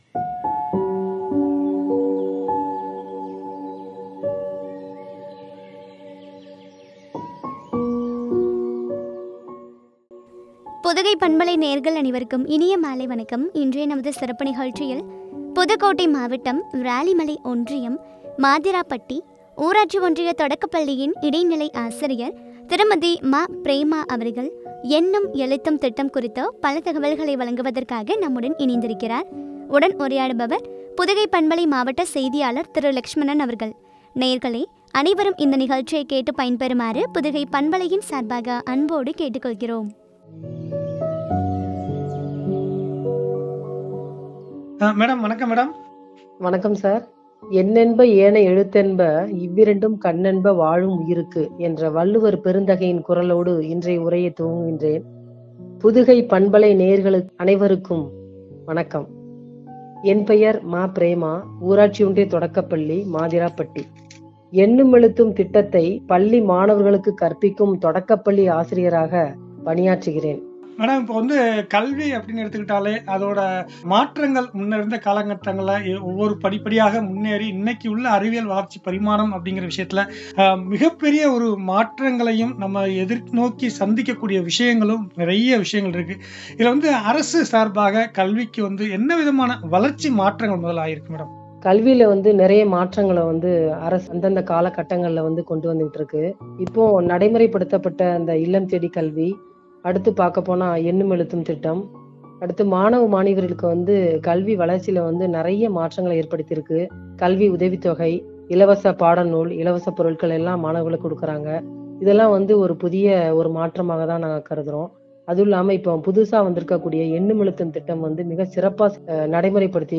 எண்ணும் எழுத்தும் திட்டம் பற்றிய உரையாடல்.